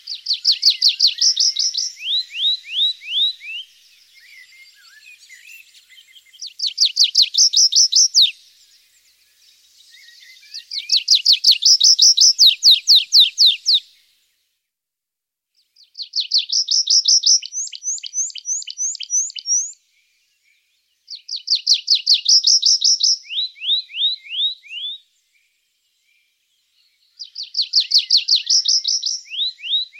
pipit-des-arbres.mp3